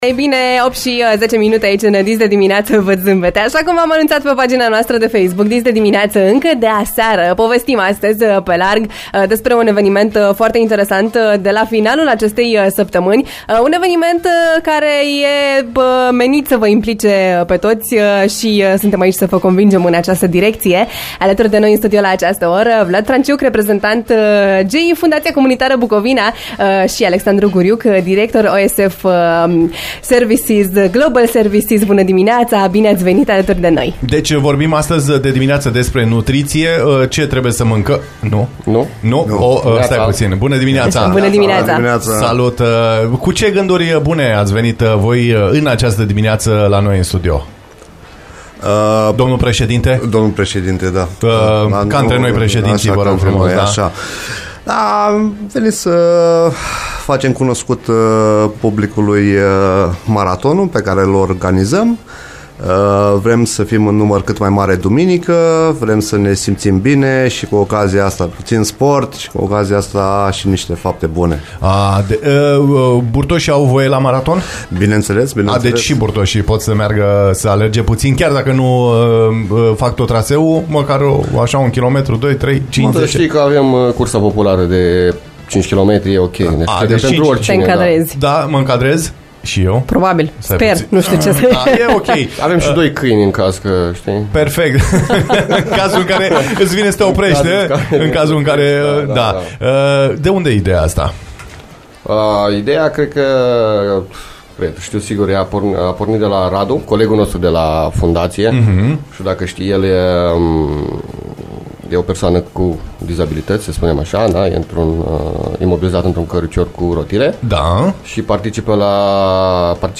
Am povestit despre originea ideii, implementare, trasee și motivație. Interviul integral poate fi ascultat mai jos: